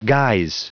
Prononciation du mot guise en anglais (fichier audio)
Prononciation du mot : guise